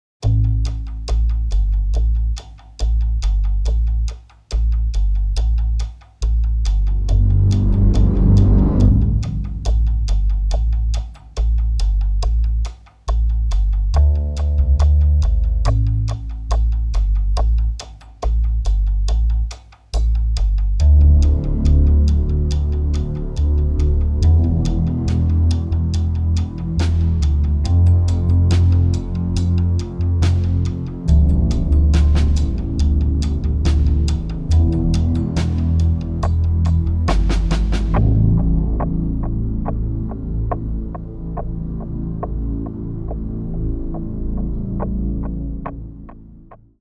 a darkly hypnotic score, suspense writing at its best